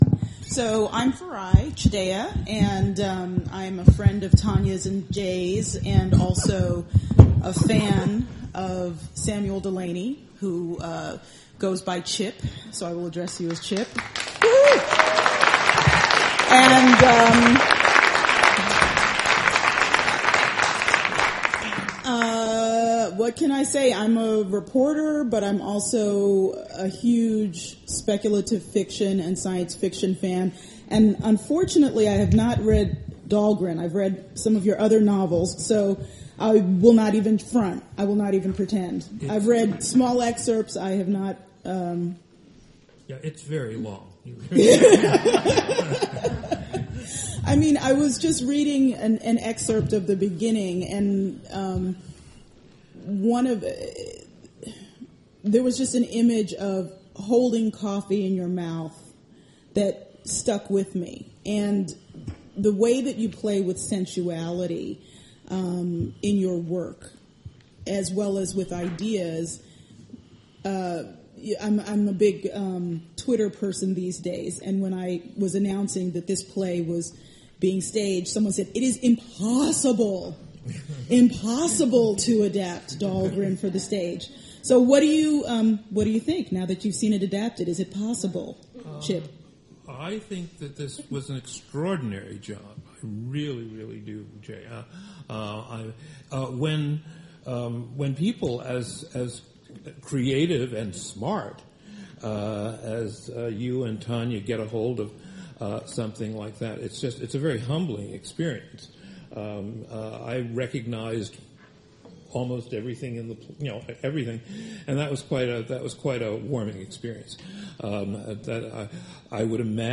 kitchen_postshow.mp3